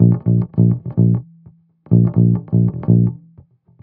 15 Bass Loop B.wav